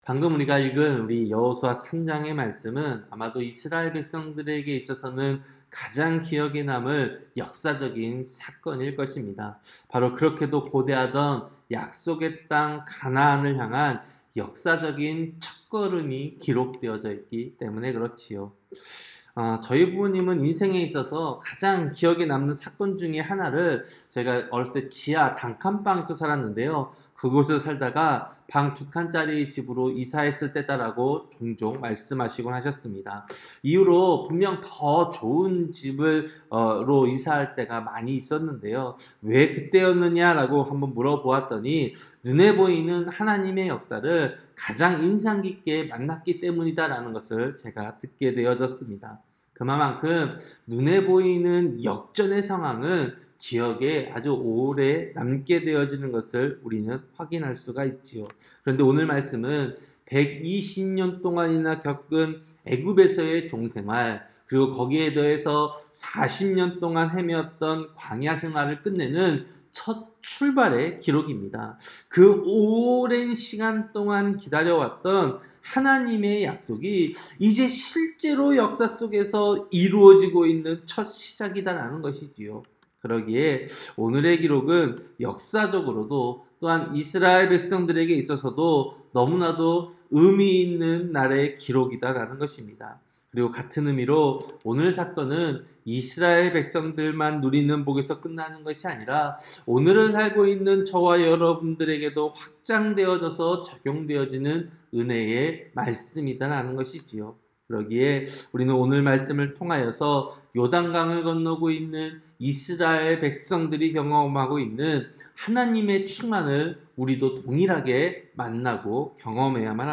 새벽설교-여호수아 3장